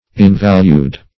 invalued - definition of invalued - synonyms, pronunciation, spelling from Free Dictionary
invalued - definition of invalued - synonyms, pronunciation, spelling from Free Dictionary Search Result for " invalued" : The Collaborative International Dictionary of English v.0.48: Invalued \In*val"ued\, a. Inestimable.